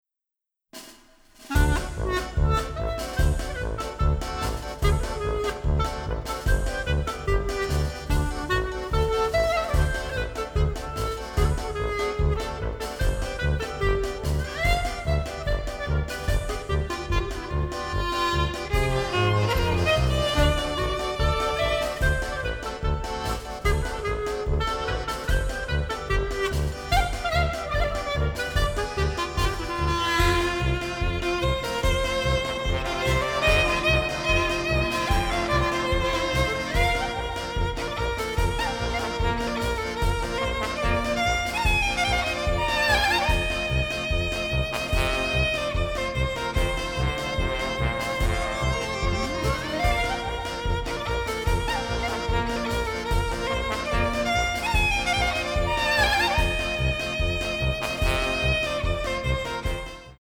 adventure score